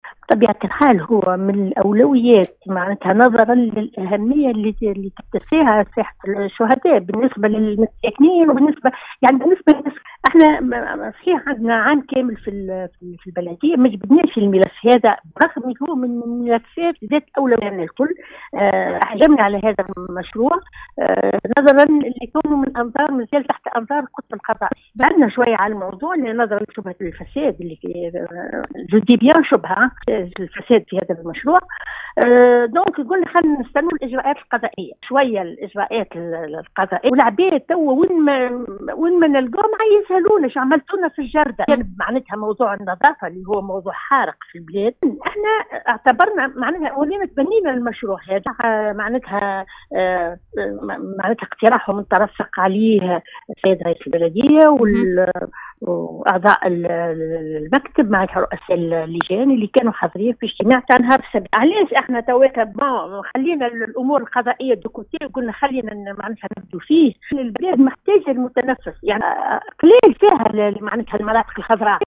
في اتصال بإذاعة السيليوم أف أم صباح اليوم